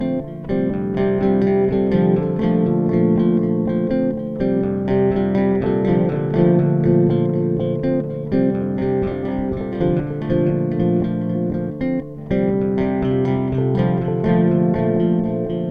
Loop  (00:15)